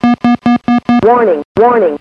warning1.wav